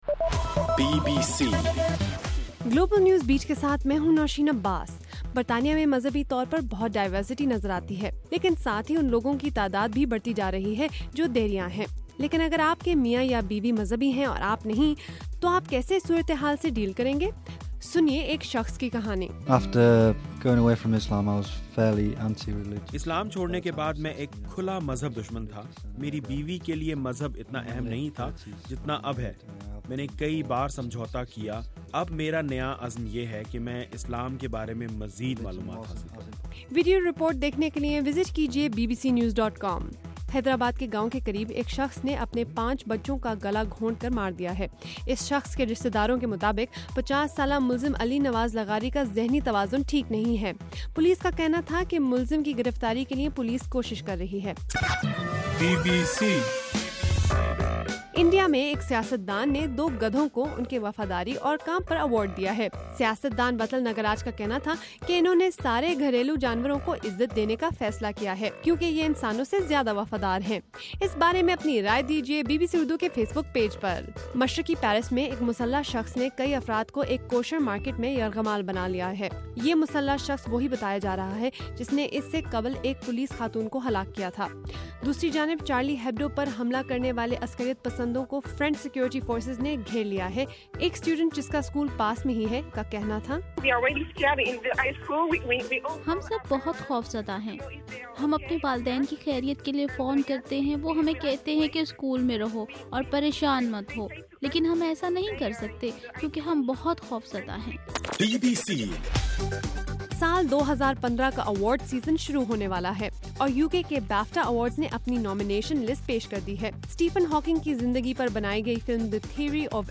جنوری 9: رات 8 بجے کا گلوبل نیوز بیٹ بُلیٹن